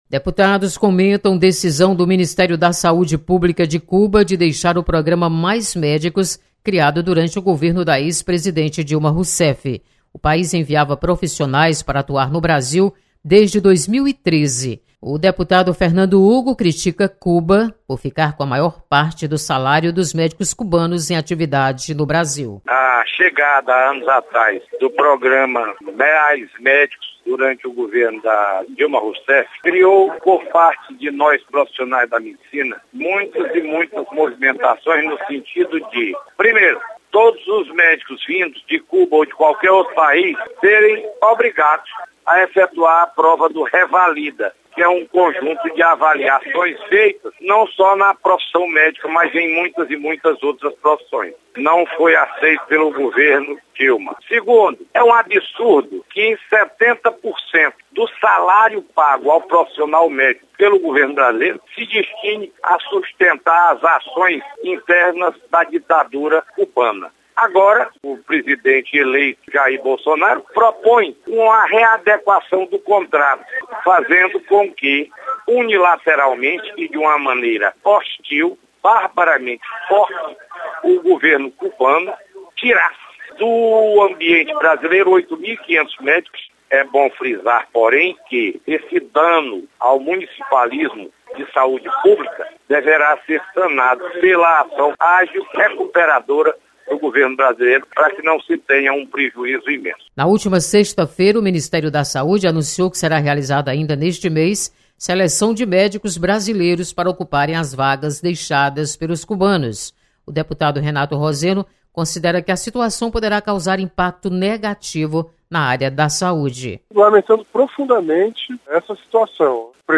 Deputados comentam sobre situação do Programa Mais Médicos.